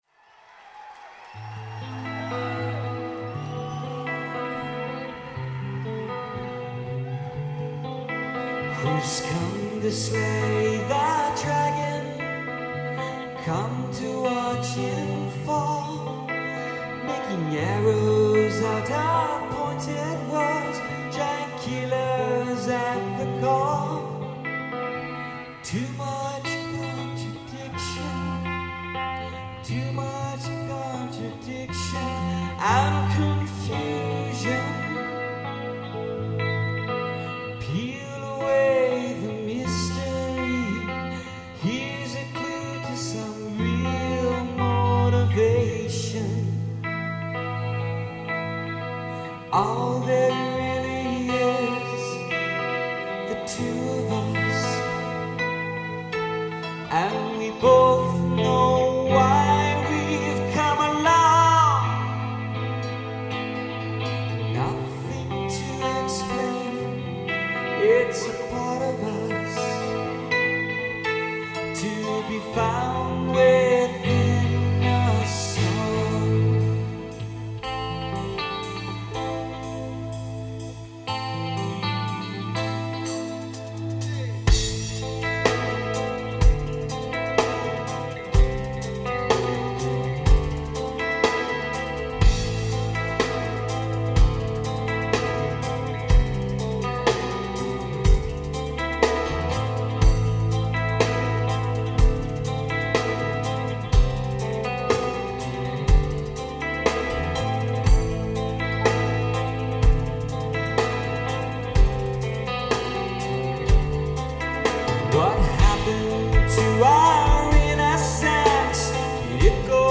medley
in a recent live concert